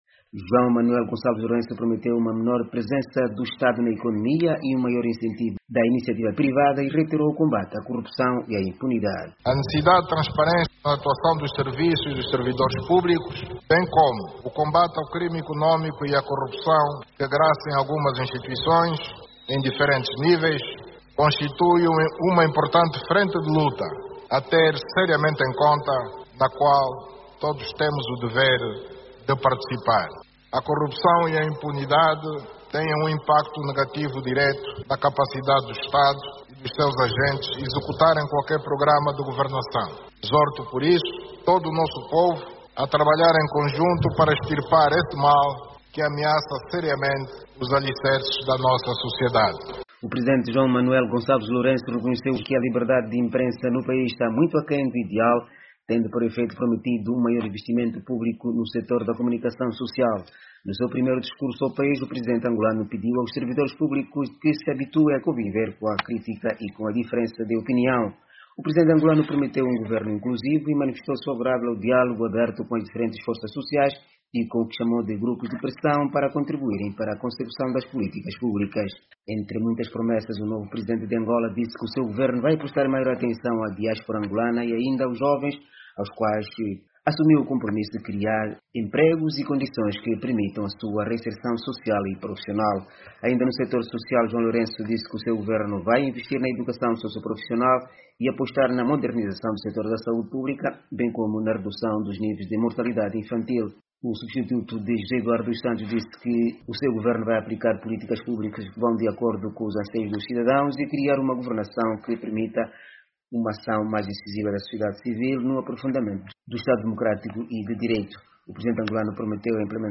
Discurso de João Lourenço na integra - 47:00